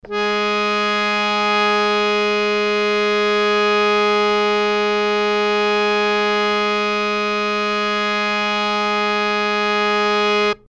harmonium
Gs3.mp3